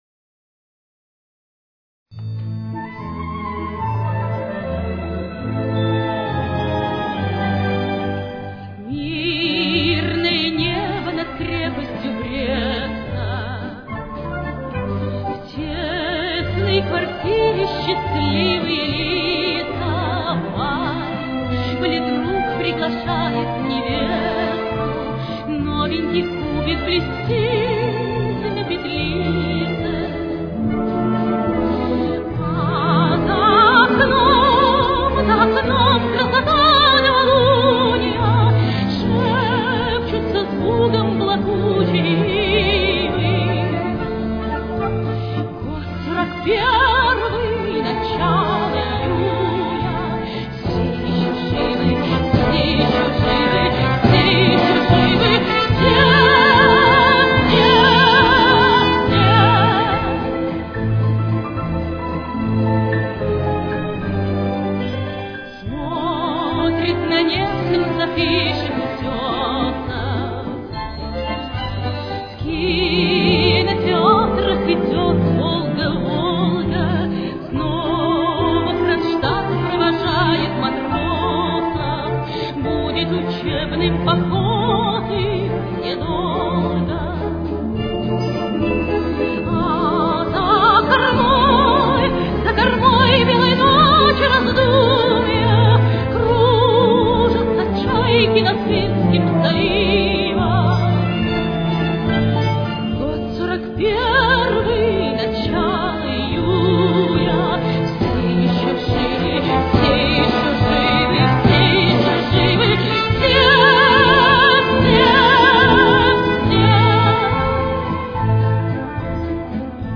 российская певица, исполнительница романсов.
с очень низким качеством (16 – 32 кБит/с)